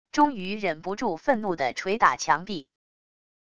终于忍不住愤怒的捶打墙壁wav音频